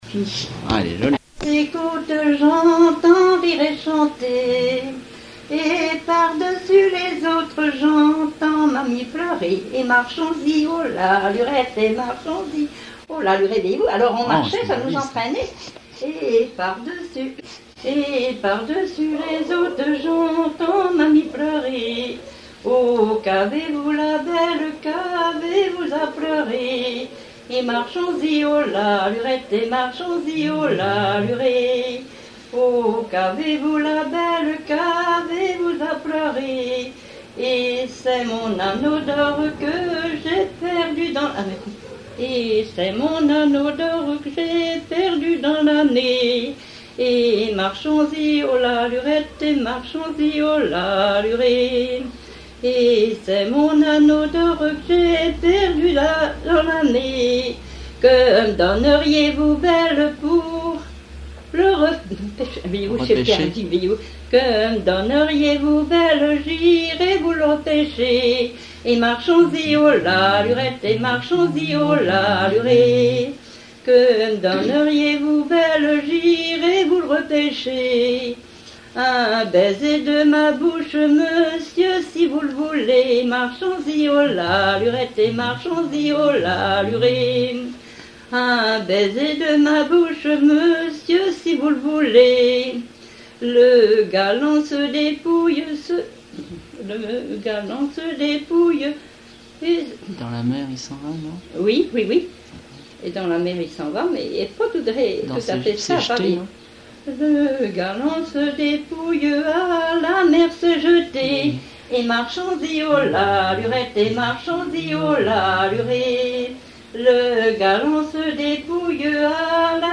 Mémoires et Patrimoines vivants - RaddO est une base de données d'archives iconographiques et sonores.
danse : ronde à trois pas
Pièce musicale inédite